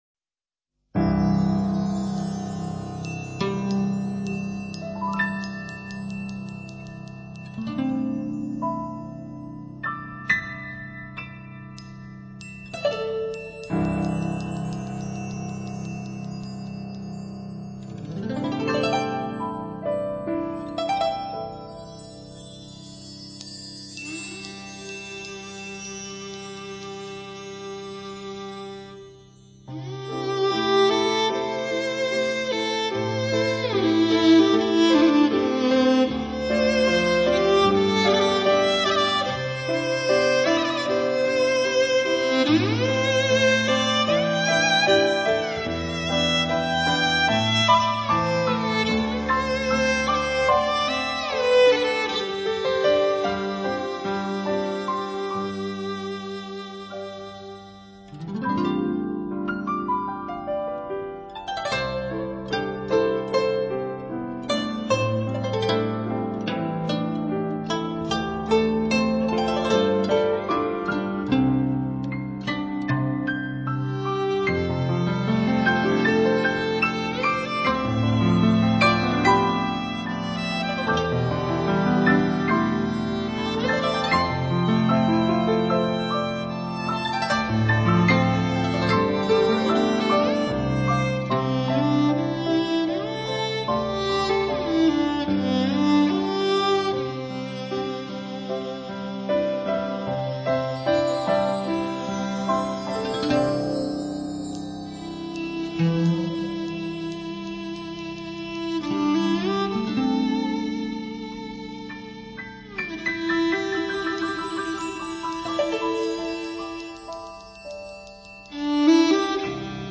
钢琴邀民乐曼舞 歌谣偕梦境玲珑
民族的明媚天空 成全了钢琴与民乐器的一段邂逅